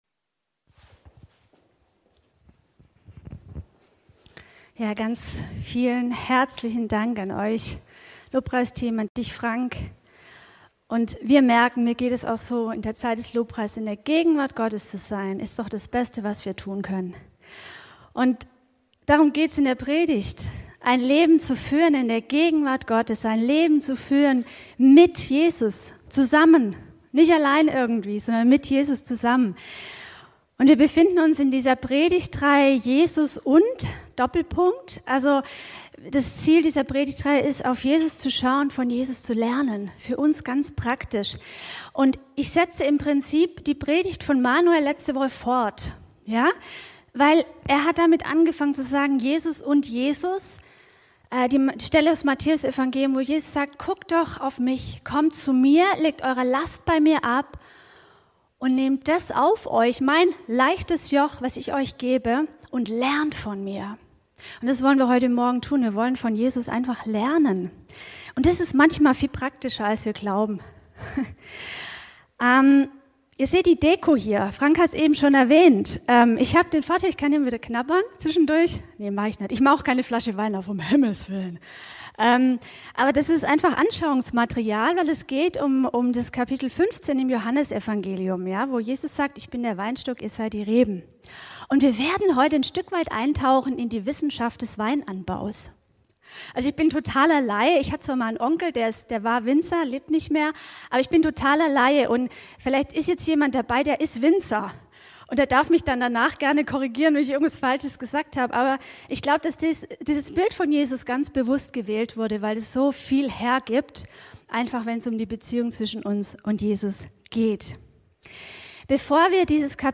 „Jesus und: ICH“ | Gottesdienst